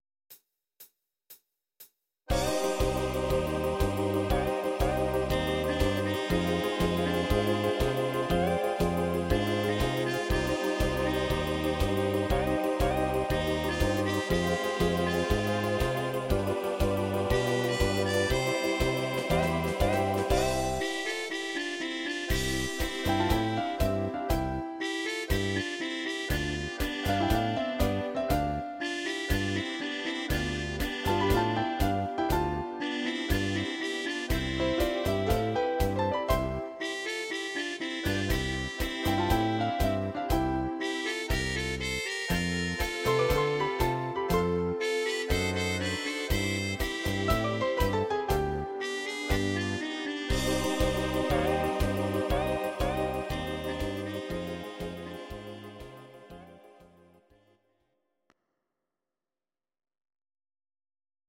Audio Recordings based on Midi-files
Oldies, German, 1950s